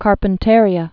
(kärpən-târē-ə), Gulf of